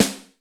RADIOSNARE.wav